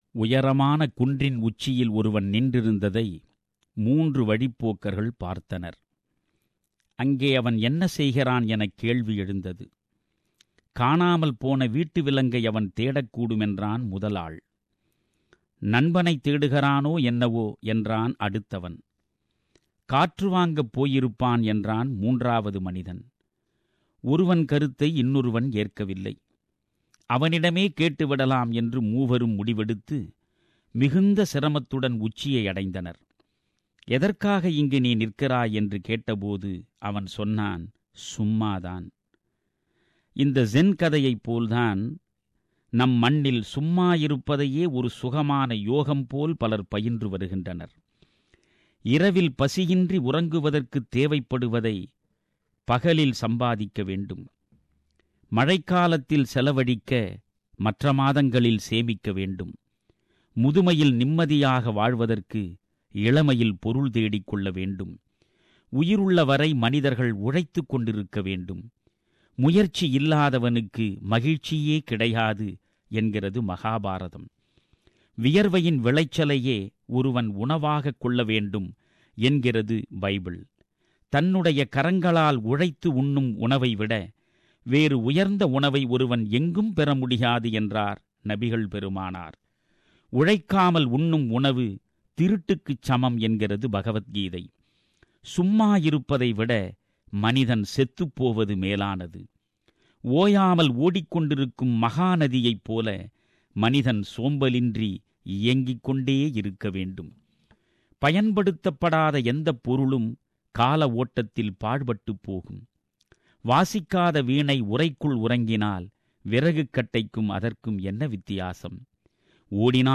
During his Australian tour, Tamilaruvi Manian visited SBS Studio and shared his views on people sitting idly.